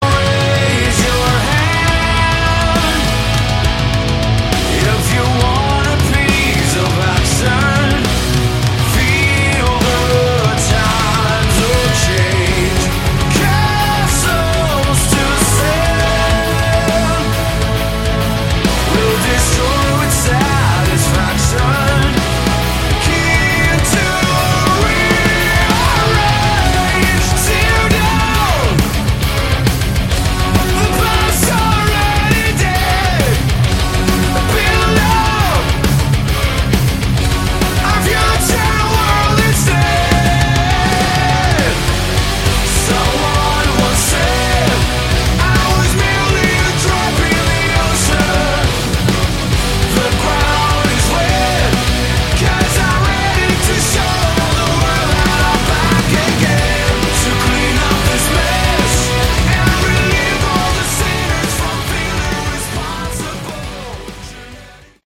Category: Melodic Metal
vocals
guitars, bass
keyboards
drums